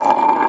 glass_m3.wav